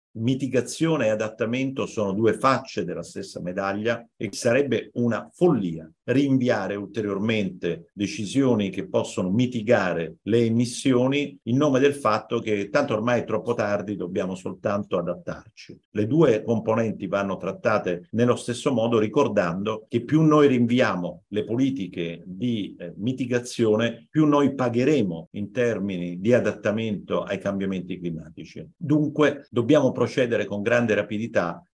Lo dice Asvis che ha presentato ieri dieci proposte per migliorare il Piano Nazionale di Adattamento ai Cambiamenti Climatici. Ascoltiamo il Direttore Scientifico Enrico Giovannini.